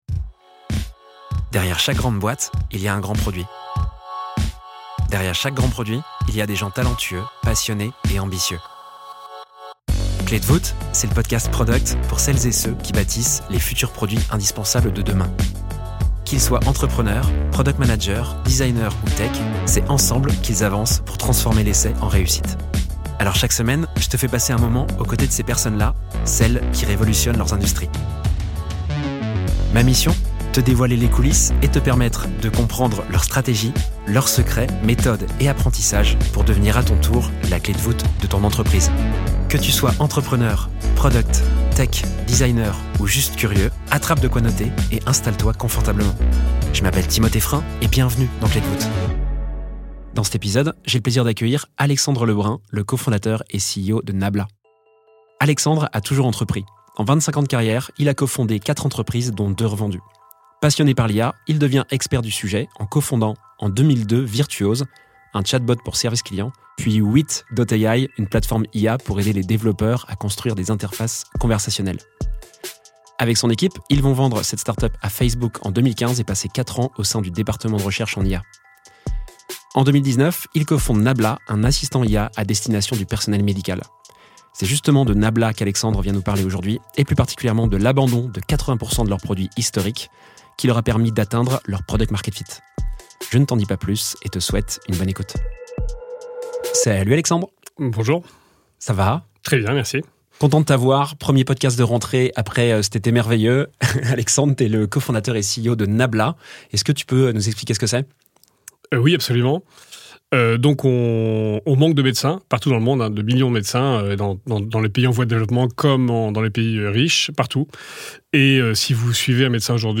Trouver son PMF, c’est difficile. Et dans la santé, c’est encore plus difficile. Dans cette conversation